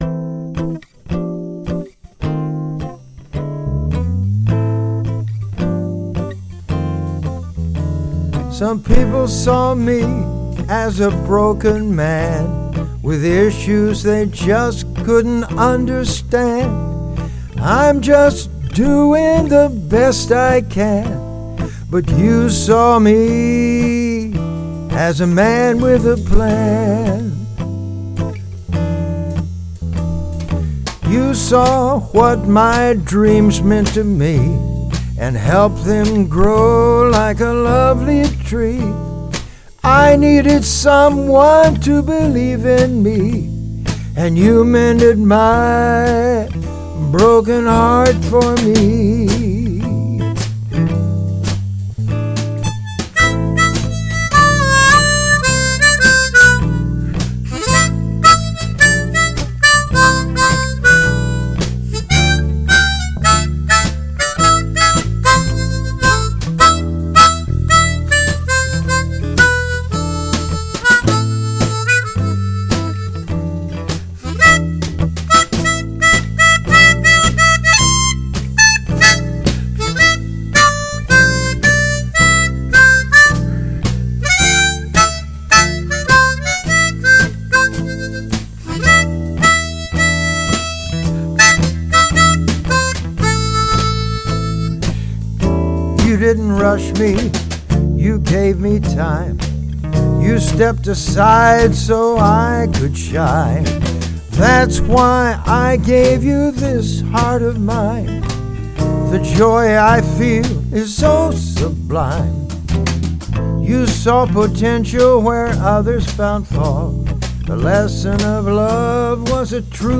Heartfelt